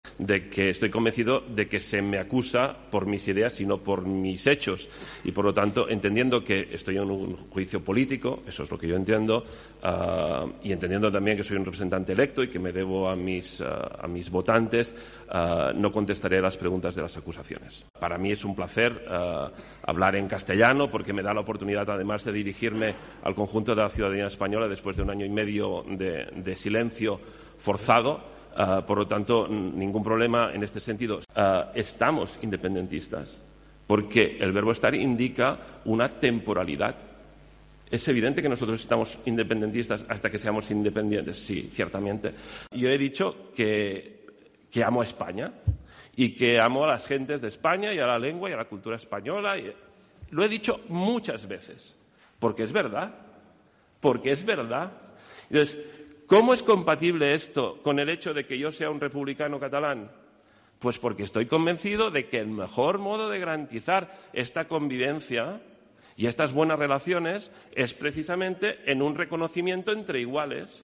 Aunque podía hacerlo en catalán ha preferido contestar en español.
Las frases de Junqueras en su declaración en el juicio del procés